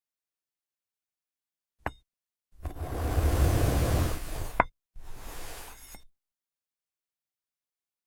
Glass Apple ASMR – clean sound effects free download
clean Mp3 Sound Effect Glass Apple ASMR – clean cuts, calm sounds.